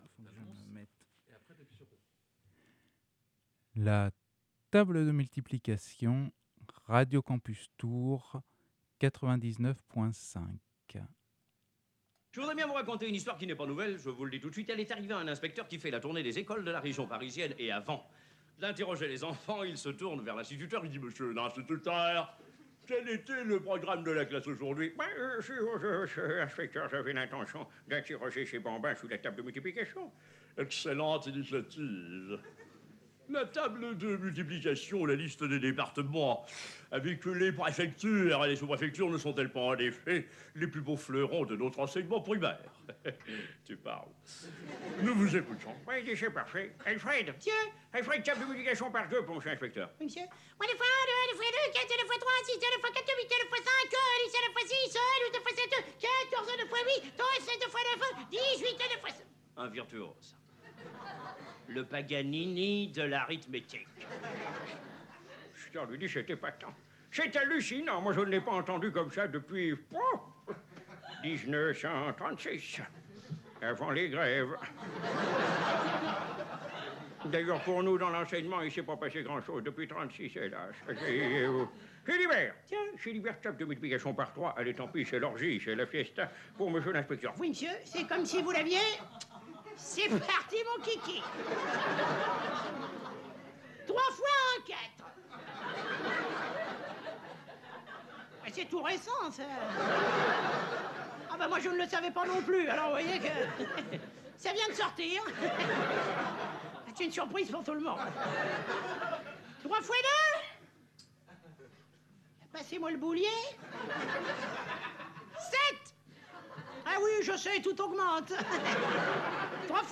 Dans son fameux sketch LA TABLE DE MULTIPLICATION, l’humoriste Jacques Bodoin nous présente une belle brochette de cancres mémorables — une des nombreuses versions sur youtube